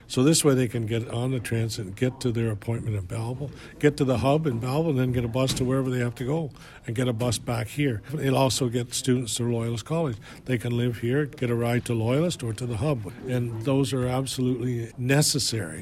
Mayor Jim Harrison tells Quinte News it’s a big ask for an expensive project, but the route between the cities would make a major difference for residents.